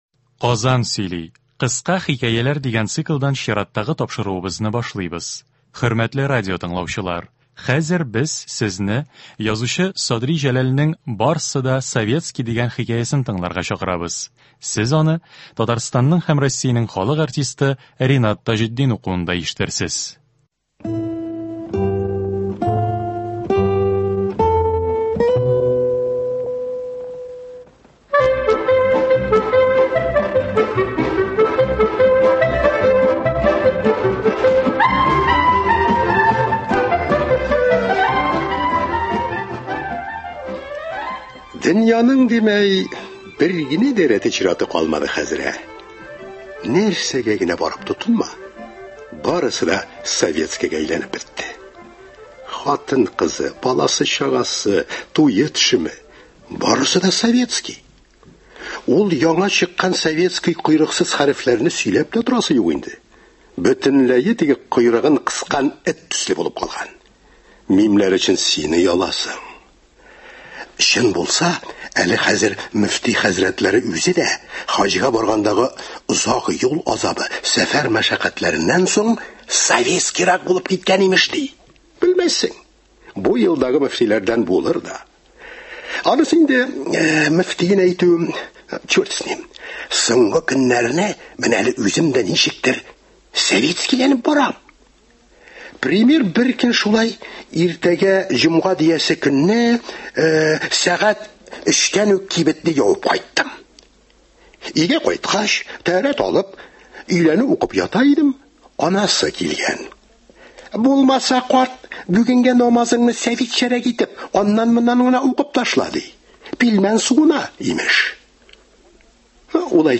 Радиотамаша.